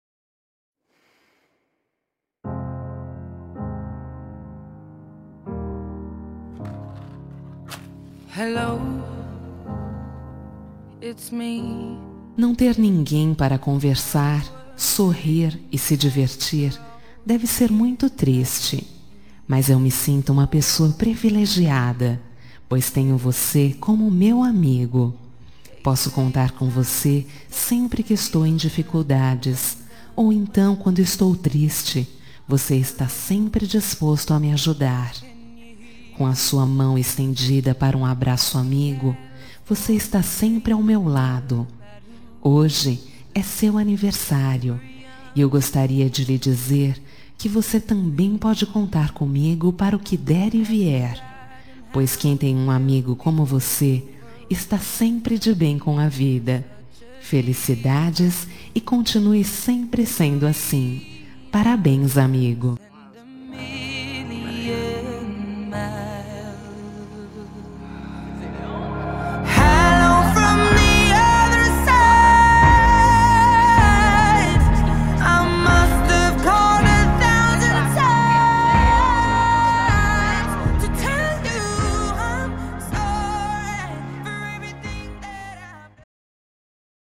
Telemensagem de Aniversário de Amigo – Voz Feminina – Cód: 1547